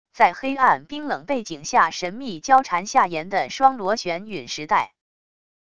在黑暗冰冷背景下神秘交缠下延的双螺旋陨石带wav音频